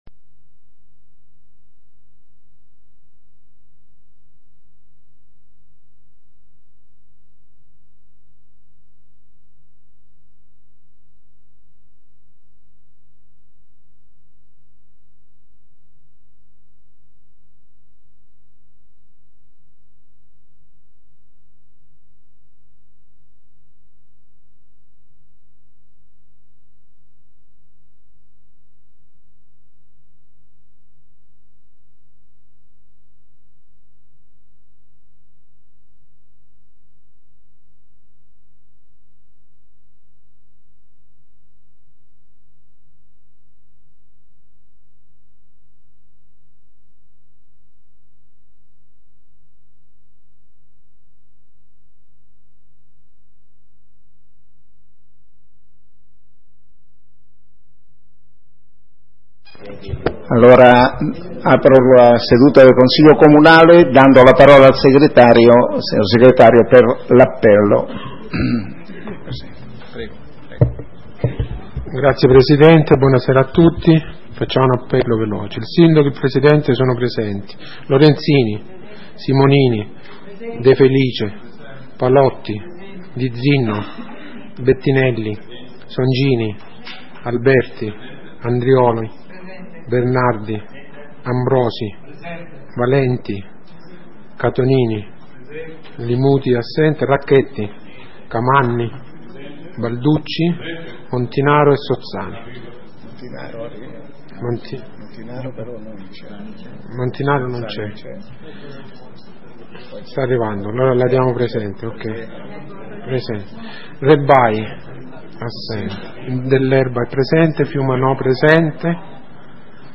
Seduta consiglio comunale del 26 febbraio 2016 - Comune di Sondrio
Ordine del giorno ed audio della seduta consiliare del Comune di Sondrio effettuata nella data sotto indicata.